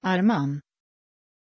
Pronunciation of Arman
pronunciation-arman-sv.mp3